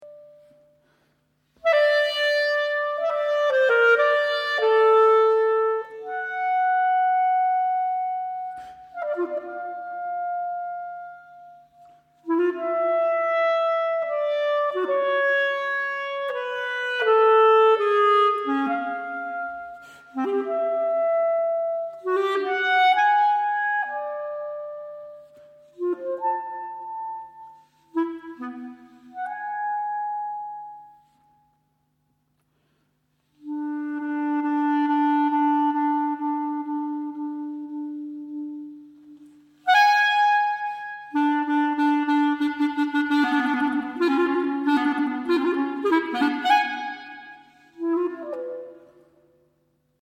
Solo Clarinet